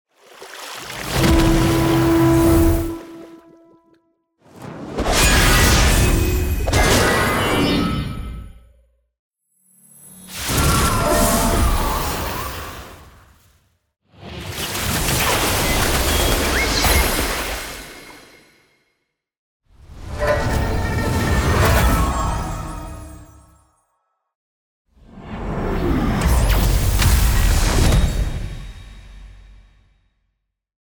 游戏音效
[技能类]
【技能类】是角色技能音效，作为游戏声音设计中的关键类别，其应用贯穿战斗系统的各个环节。无论是火焰技能的爆燃轰鸣、水流法术的涌动激溅，还是雷电法术的撕裂电光、土系技能的地裂崩塌，亦或是刀剑挥斩的破空声、重锤砸地的震荡声、扇类武器带起的气流呼啸，乃至技能蓄力时的能量聚集声、角色怒吼与符文激活的瞬发音效，都属于技能音效的范畴。
以大型MMORPG为例，当角色释放终极技能时，伴随华丽特效而来的层层声音，从低频预判音、能量升腾到最终爆发的混合轰鸣，不仅精准传递技能属性与威力，更强化了战斗的节奏感与情绪张力。